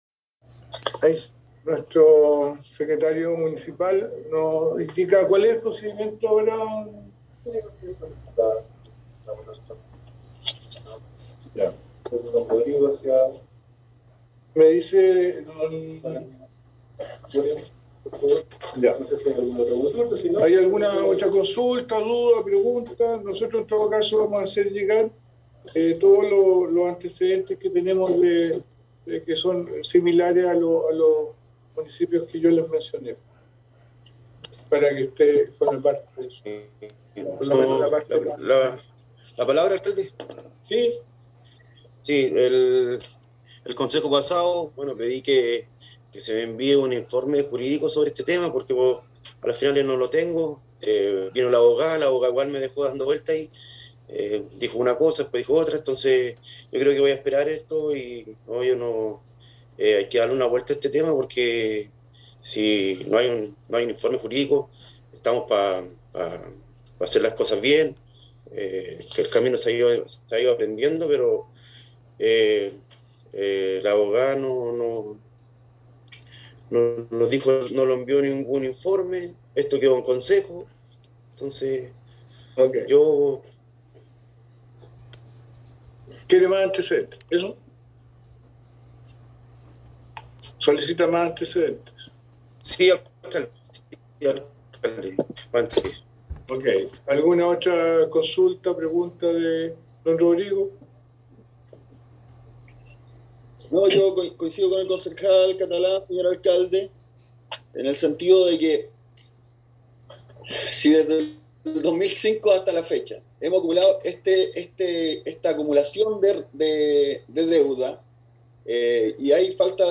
LA VOTACIÓN ….
VOTACION-CONCEJO-MUNICIPAL.mp3